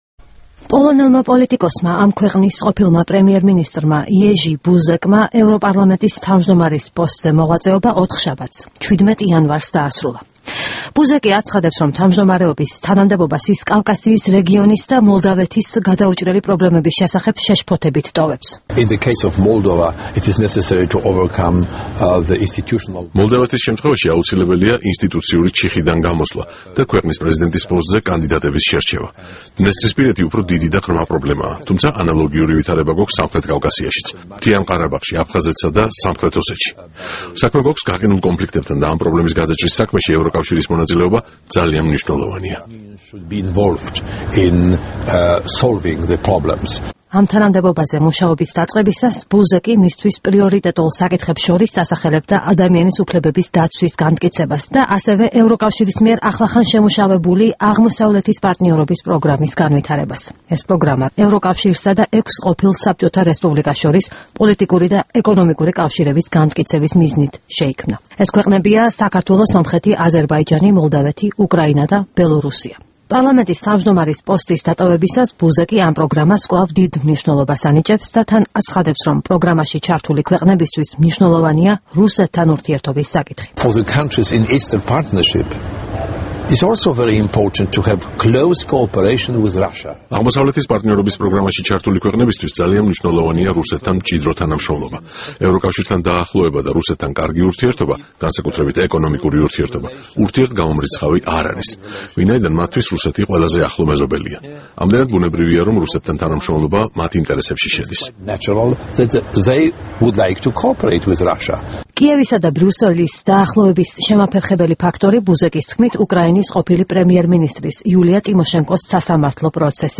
ინტერვიუ იეჟი ბუზეკთან